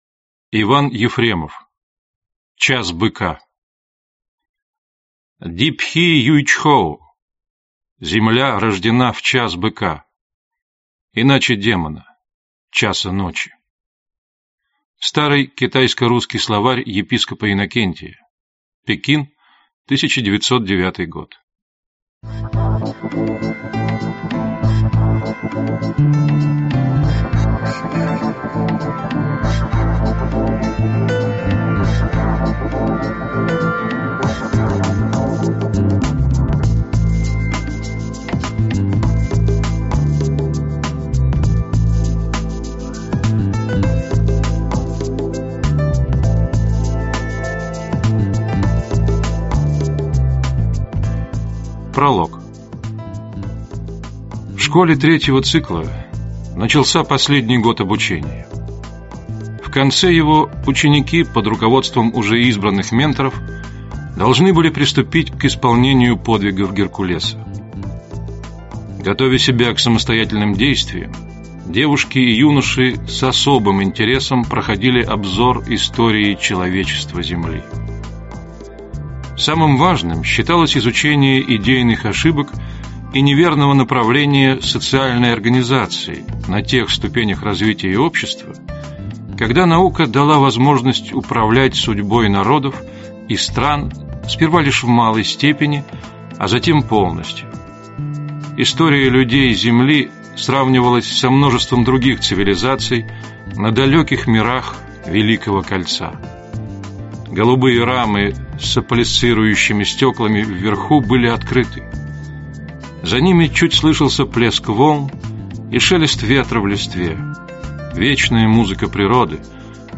Аудиокнига Час Быка - купить, скачать и слушать онлайн | КнигоПоиск